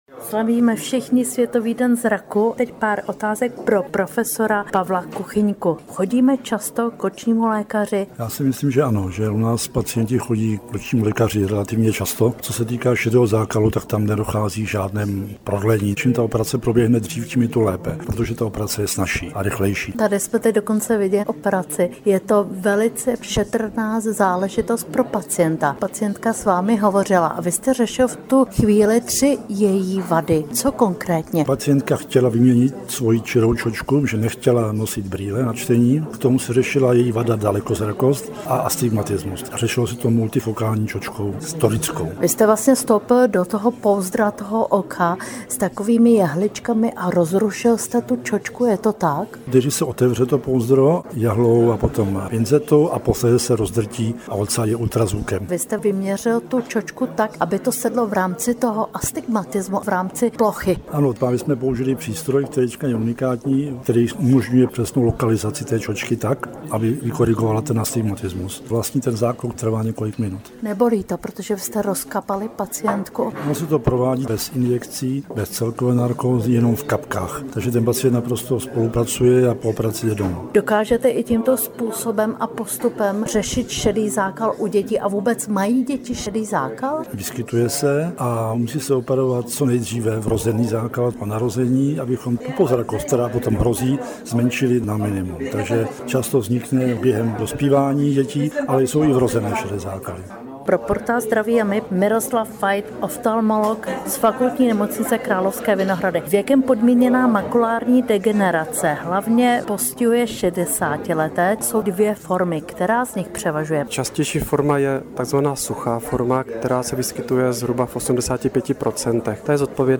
AUDI rozhovor: Co jsme se od odborníků dozvěděli?